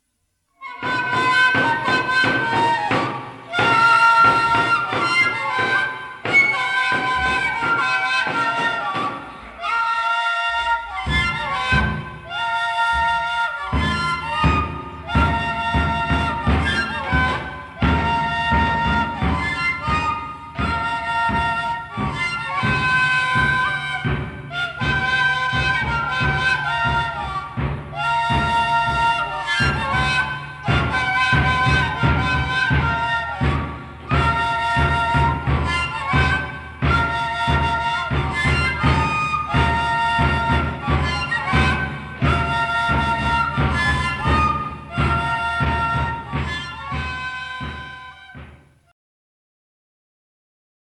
Tarqueada instrumental